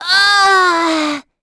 Gremory-Vox_Damage_03.wav